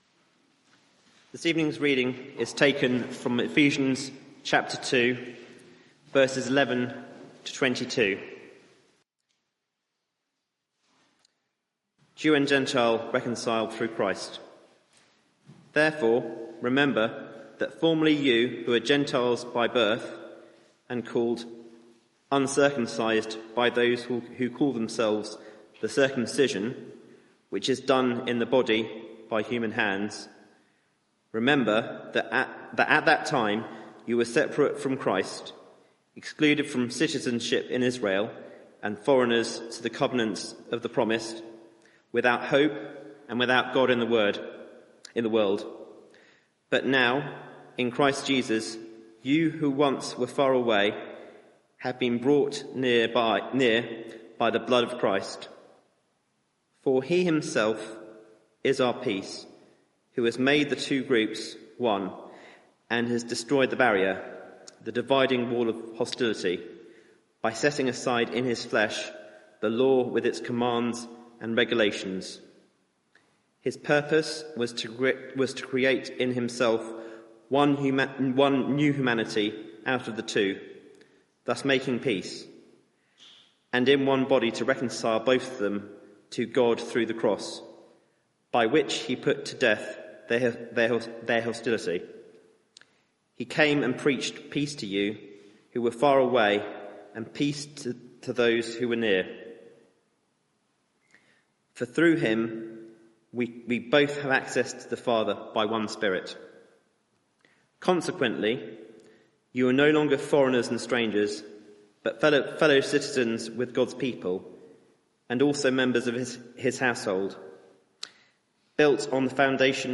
Media for 6:30pm Service on Sun 16th Jun 2024 18:30 Speaker
Sermon